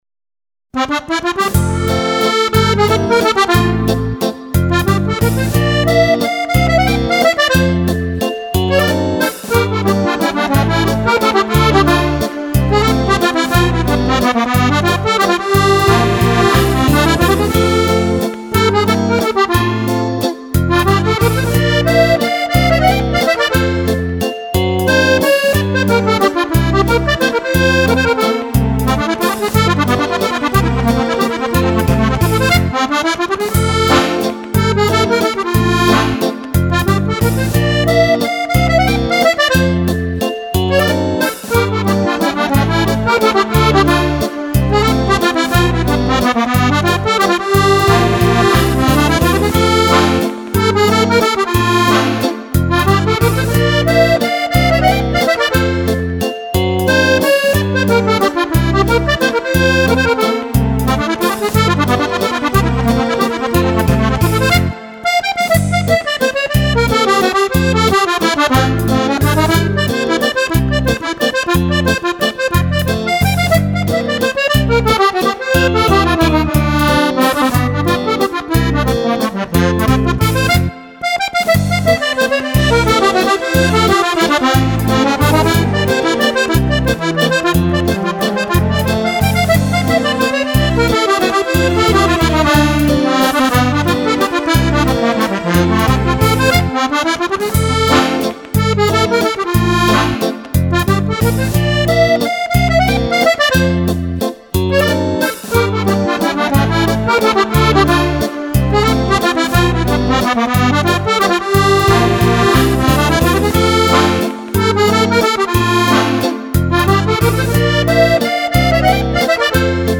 Valzer
Fisarmonica